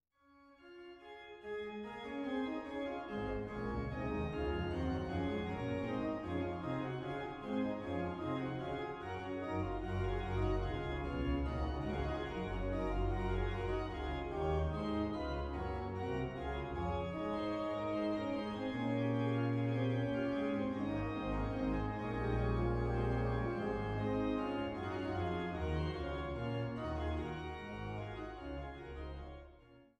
Trost-Orgel der Schlosskirche Altenburg